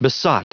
Prononciation du mot besot en anglais (fichier audio)
Prononciation du mot : besot